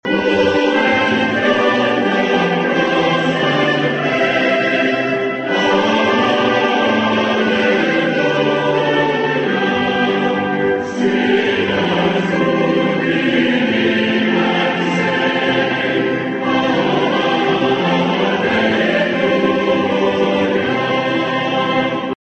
Well over 100 vehicles stopped at the Lyon County Fairgrounds for the Messiah Lutheran Church drive-up Easter worship service Sunday.
Drizzle and light showers didn’t deter hundreds of area residents from starting their Easter morning at the Lyon County Fairgrounds.
1204-messiah-music.mp3